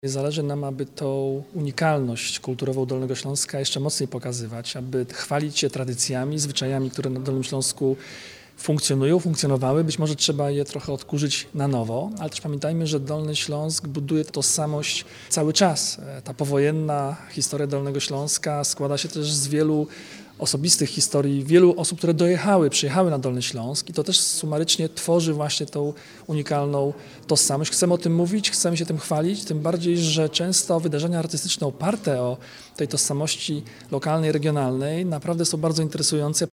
– Konkurs ma na celu wzmacnianie lokalnych zwyczajów, tradycji, doświadczeń – mówi Jarosław Rabczenko, członek Zarządu Województwa Dolnośląskiego.